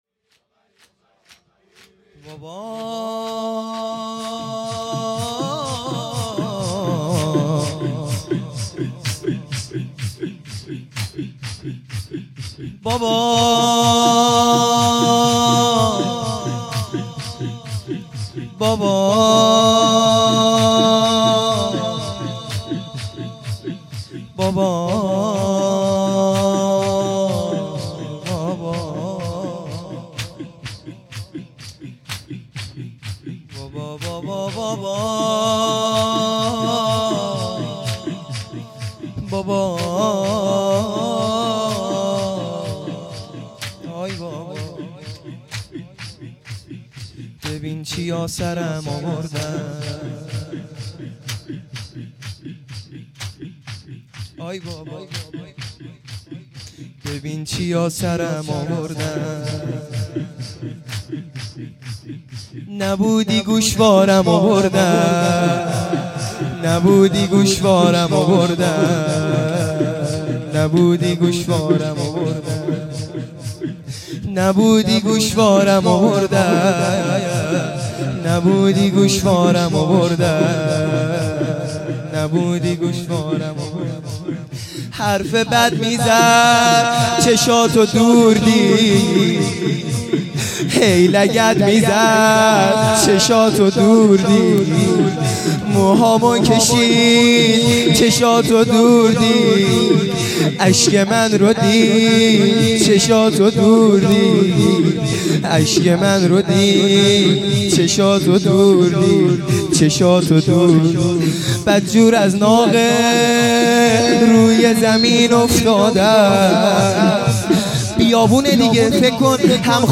لطمه زنی | ببین چیا سرم
دهه اول صفر | شب دوم